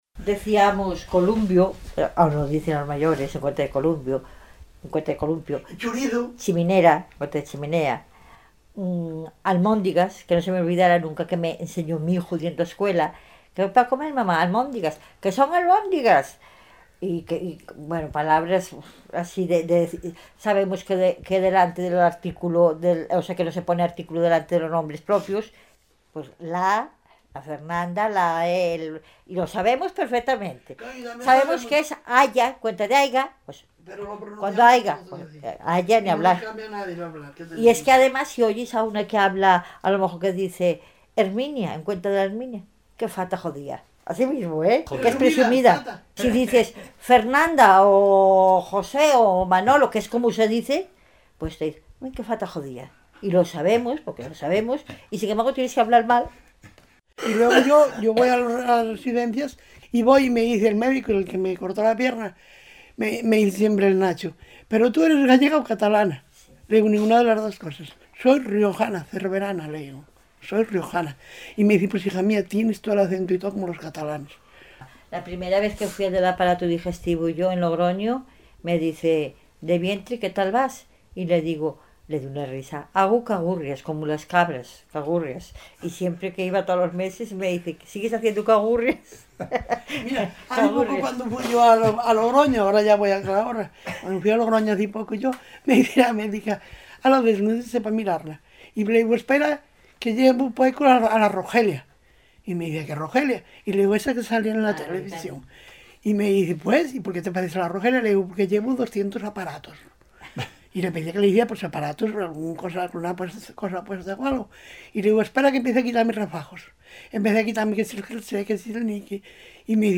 Clasificación: Testimonios dialectales, testimonios vitales
Lugar y fecha de recogida: Cervera del Río Alhama, 17 de febrero de 2002
Los hombres y las mujeres de Cervera del río Alhama son conscientes de que tienen una forma de hablar muy peculiar, no solo por el vocabulario arcaico y genuino que utilizan en sus conversaciones, también por la pronunciación de los fonemas «e» y «o» que son casi «i» y «u», por su gran expresividad y por esa entonación que llega a confundir a quienes no la conocen, con una sonoridad que recuerda unas veces al catalán y otras al gallego o al asturiano.